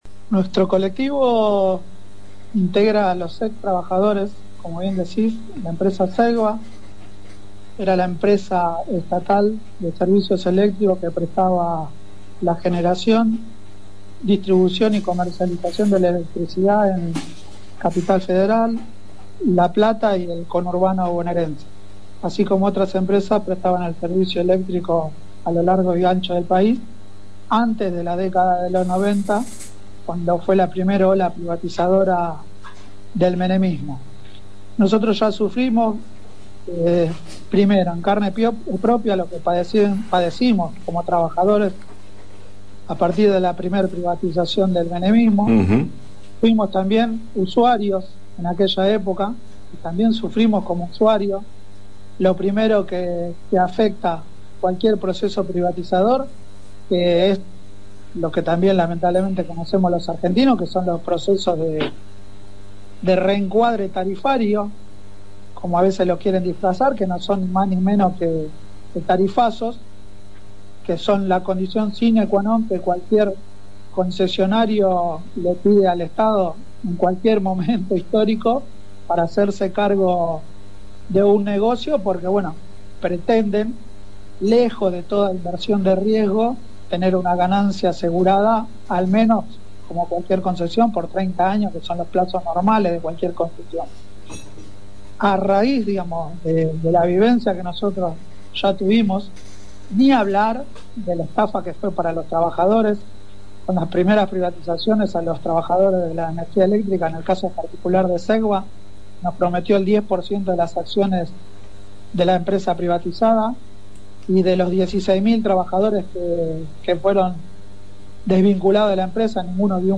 Ex-Empleados-de-Segba.mp3